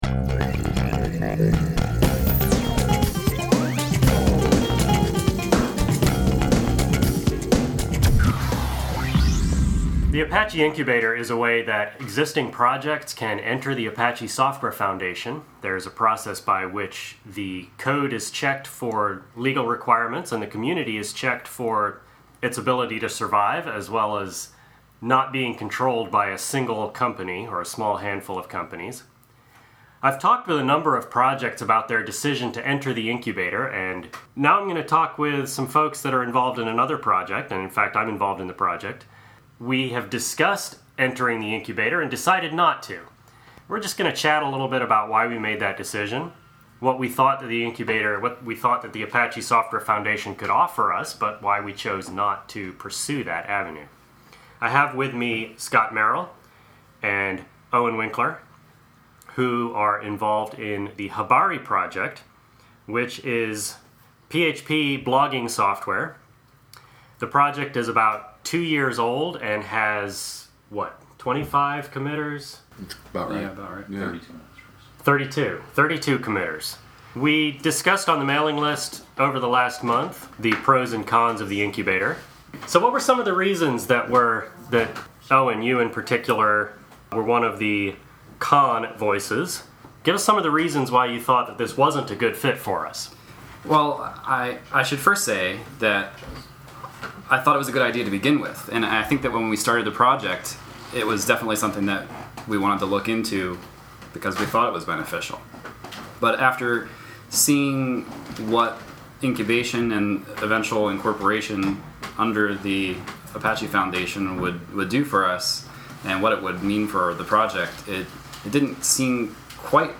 We’ve talked with a number of projects who have entered the incubator. Here’s a conversation with some members of the Habari project – a project that decided not to, and their reasons for that decision.